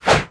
su_swing_2.wav